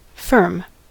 firm: Wikimedia Commons US English Pronunciations
En-us-firm.WAV